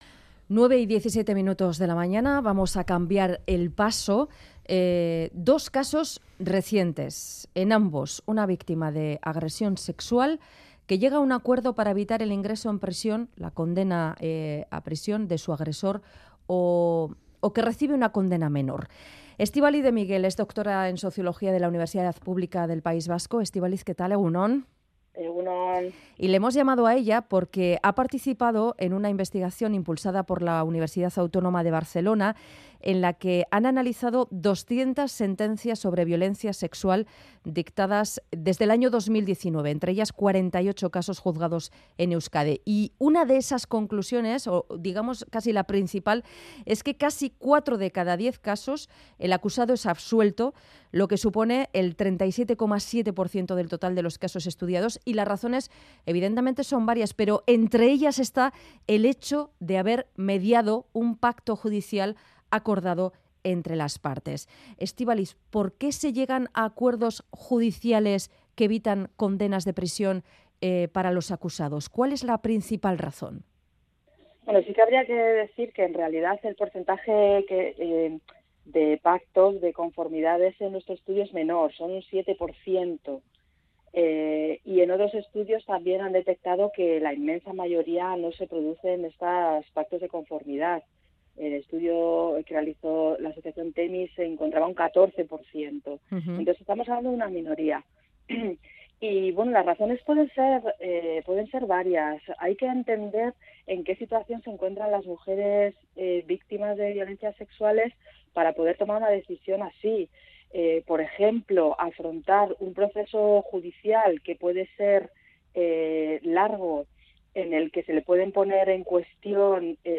Audio: Dos casos recientes en donde las víctimas de una agresión sexual llegan a un acuerdo que evita el ingreso en prisión de su agresor o una condena menor nos ha hecho reflexionar sobre ello. Estrevistamos